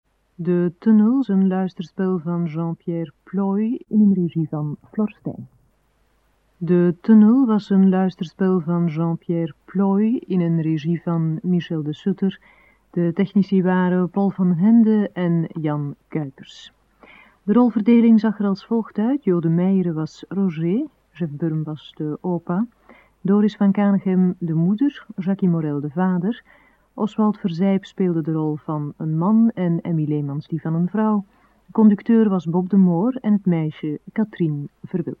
Hoorspel anders: De tunnel.